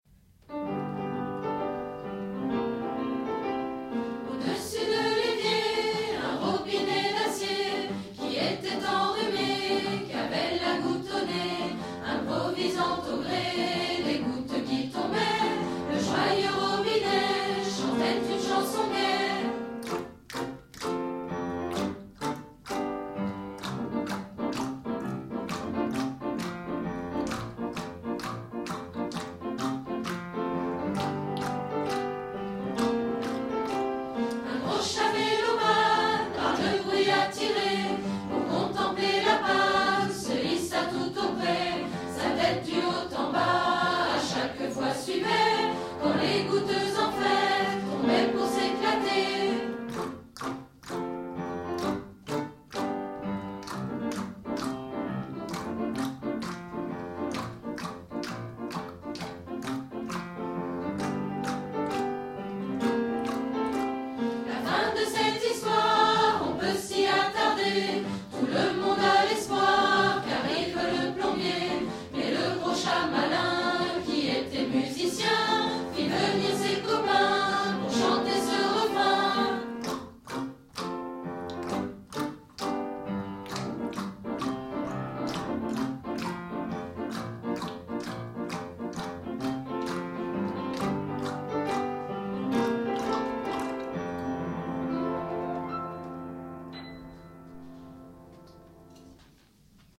Version chantée 2 :